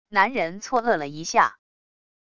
男人错愕了一下wav音频